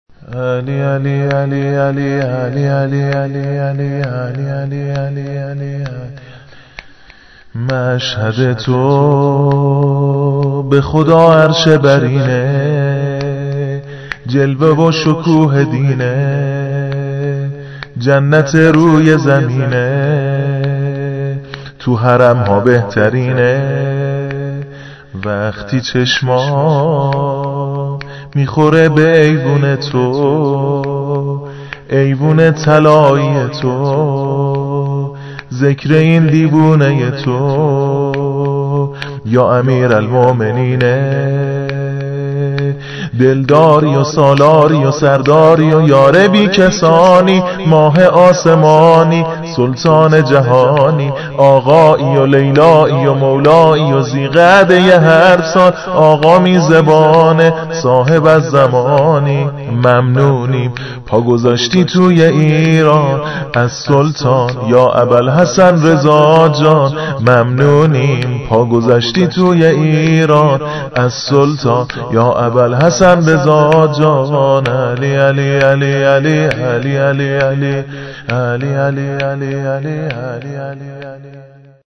شور ، سرود